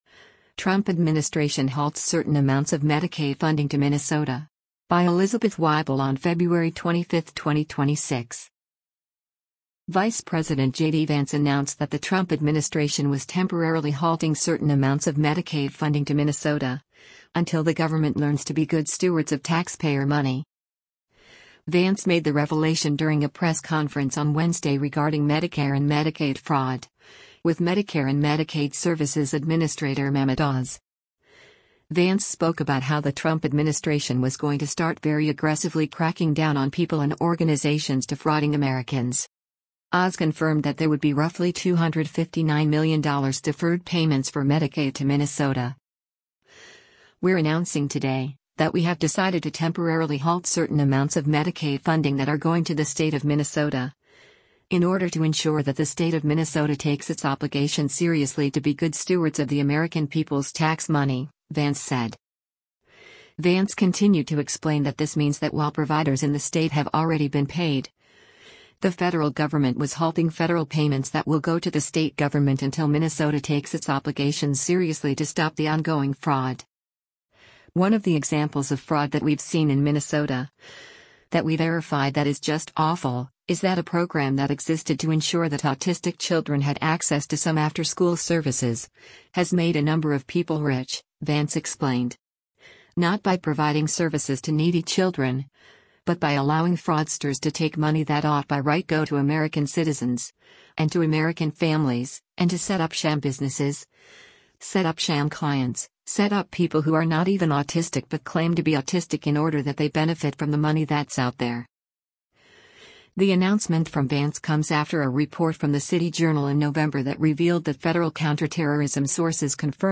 Vance made the revelation during a press conference on Wednesday regarding Medicare and Medicaid fraud, with Medicare & Medicaid Services Administrator Mehmet Oz.